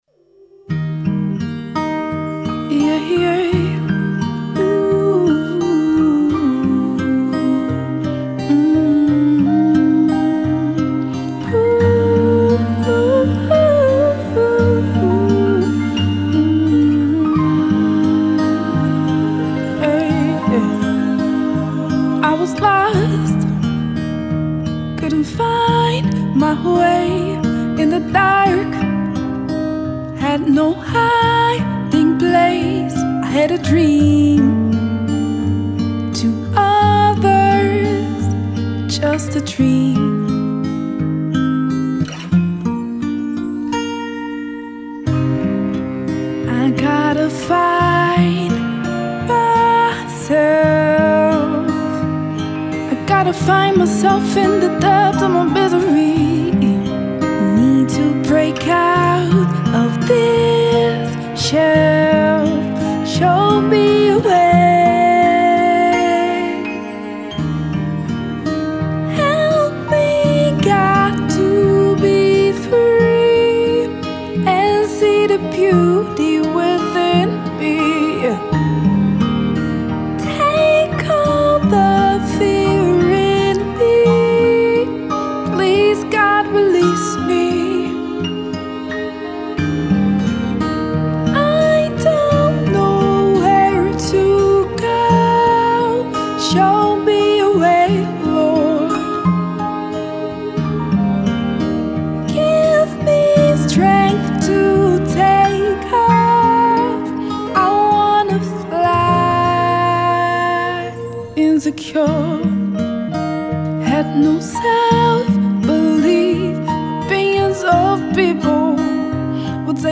topic: Singing